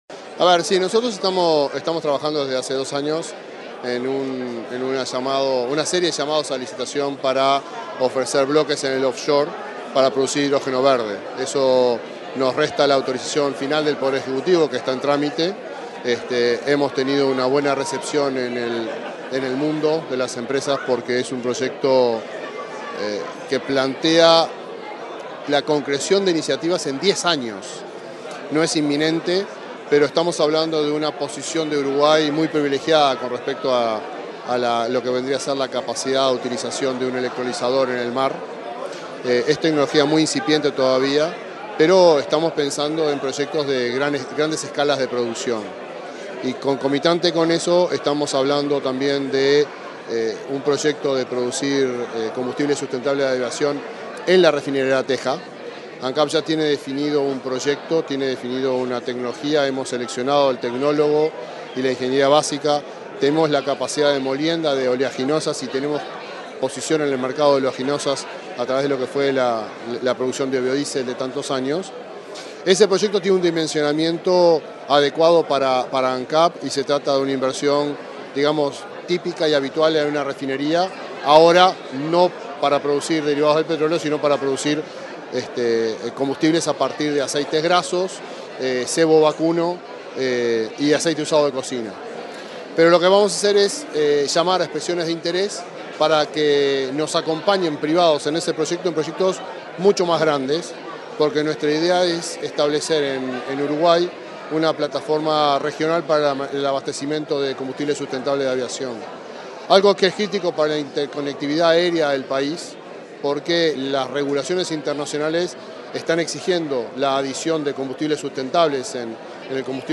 Declaraciones del presidente de Ancap, Alejandro Stipanicic
El presidente de Ancap, Alejandro Stipanicic, dialogó con la prensa, este martes 21 en Montevideo, acerca de varios proyectos de la empresa petrolera.